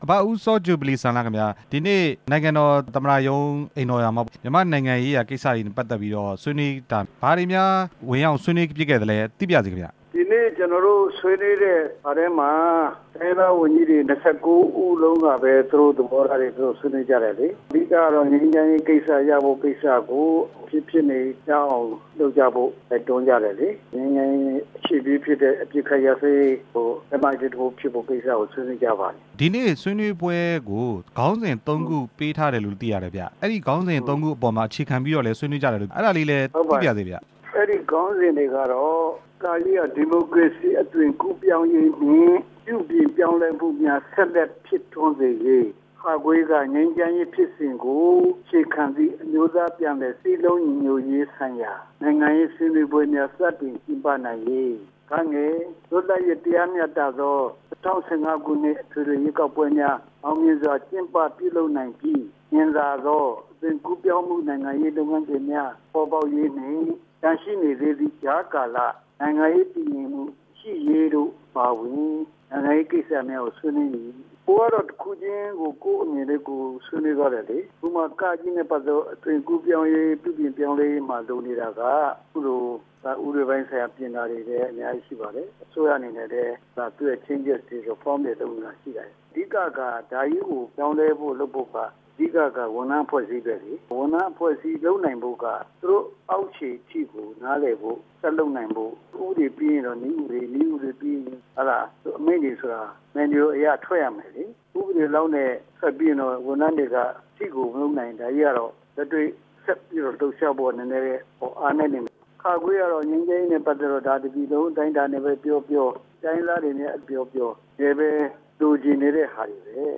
ပဲခူးတိုင်းဒေသကြီး ကရင်တိုင်းရင်းသားရေးရာဝန်ကြီး ဦးစောဂျူဗလီစံလှနဲ့ မေးမြန်းချက်